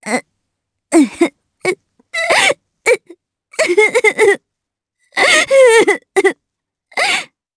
Cecilia-Vox_Sad_jp.wav